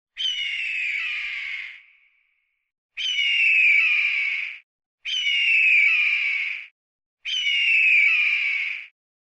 Звуки ястреба
Звук ястреба в полете